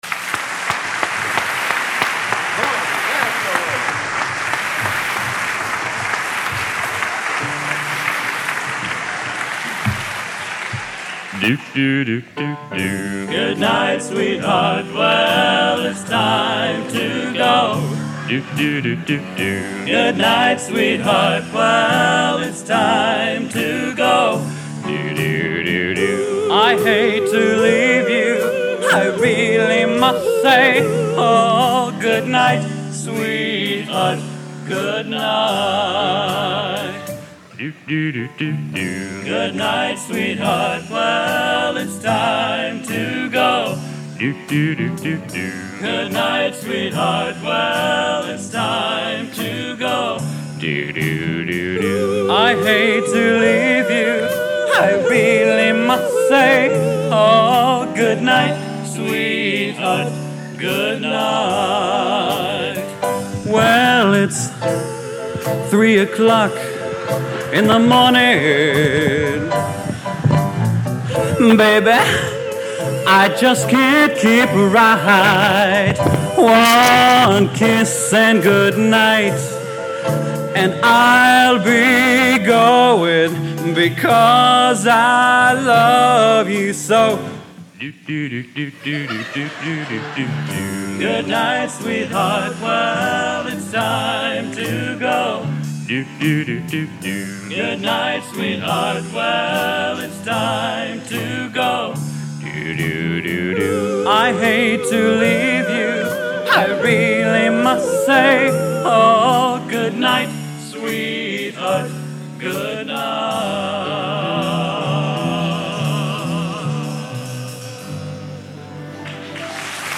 Genre: Doo Wop | Type: End of Season